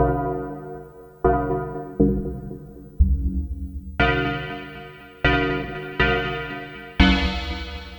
Mallet World.wav